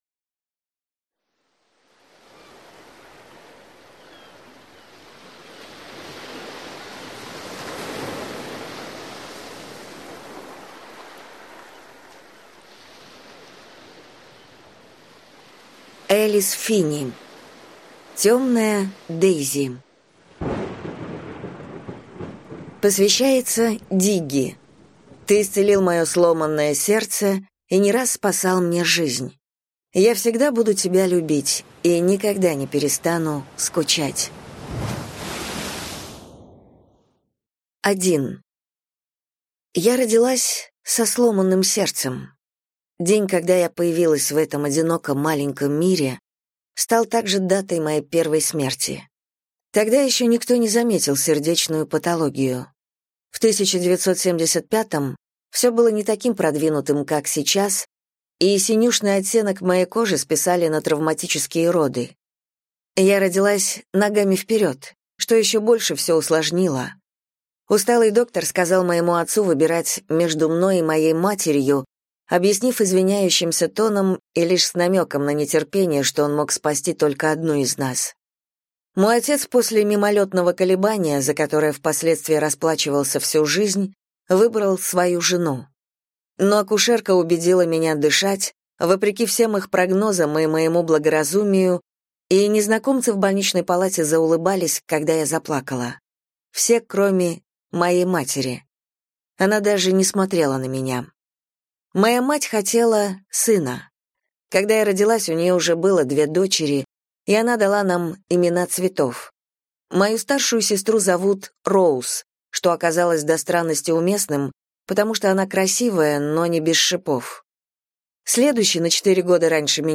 Аудиокнига Темная Дейзи | Библиотека аудиокниг